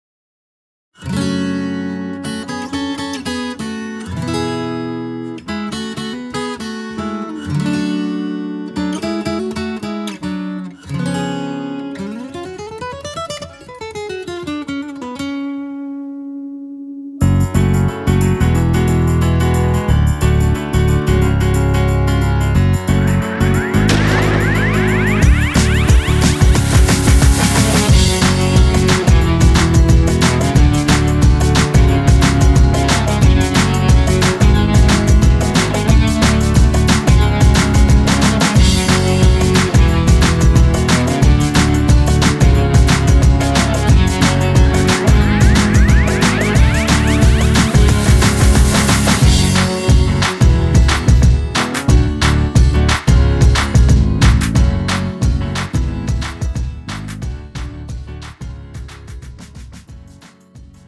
음정 (-1키)
장르 가요 구분 Premium MR
Premium MR은 프로 무대, 웨딩, 이벤트에 최적화된 고급 반주입니다.